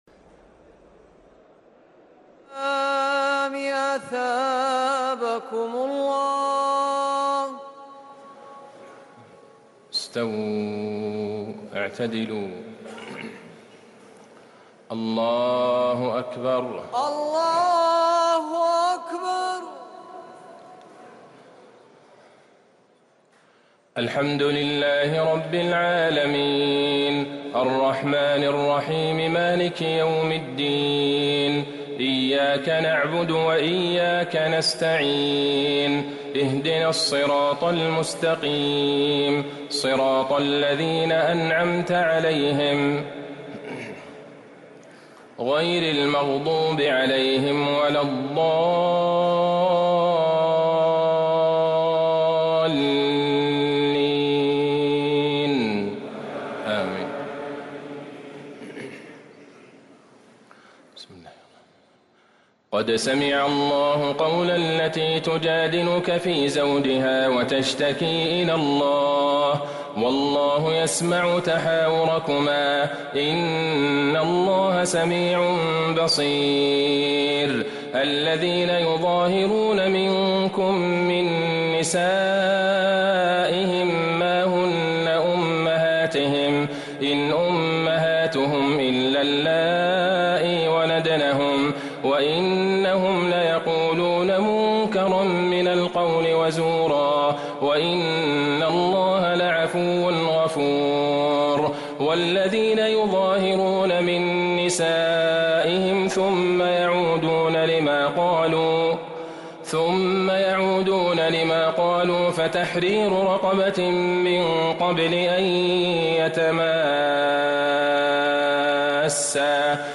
تراويح ليلة 28 رمضان 1444هـ من سورة المجادلة إلى سورة الجمعة | taraweeh 28 st niqht Ramadan 1444H from Surah Al-Mujaadila to Al-Jumu'a > تراويح الحرم النبوي عام 1444 🕌 > التراويح - تلاوات الحرمين